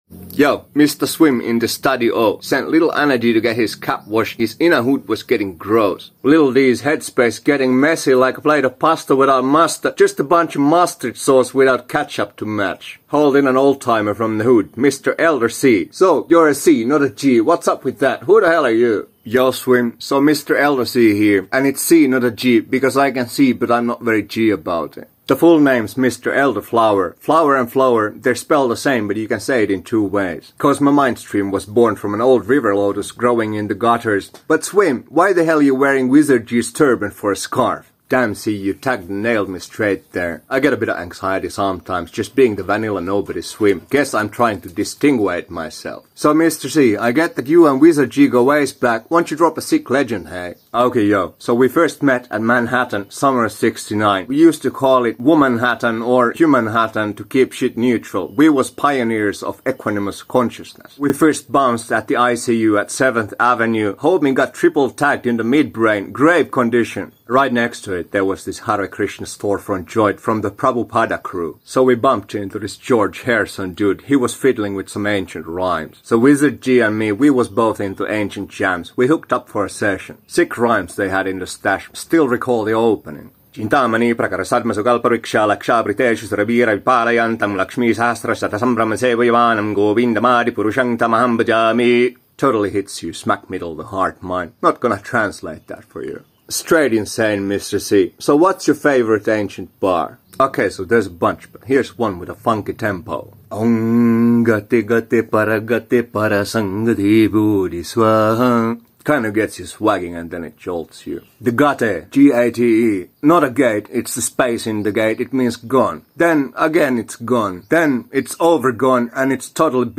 Actually sick beats & this dude's like over-gone insane in the mid-brain!